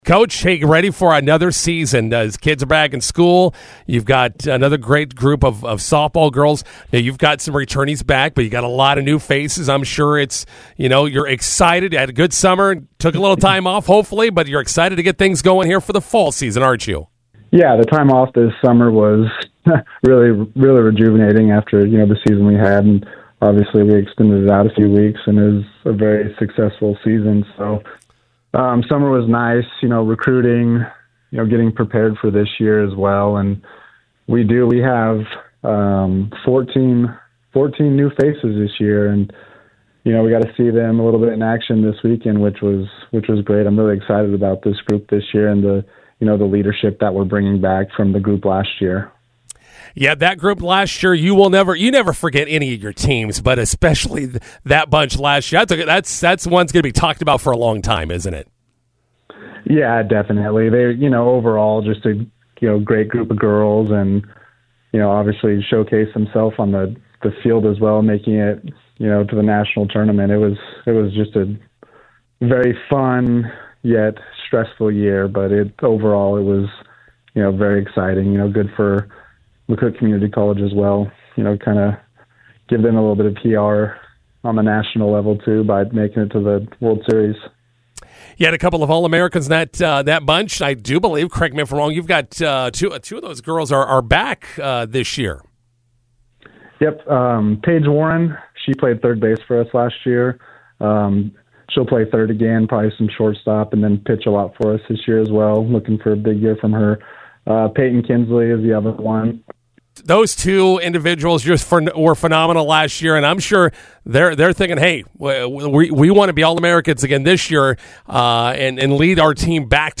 INTERVIEW: McCook Community College softball kicks off fall season.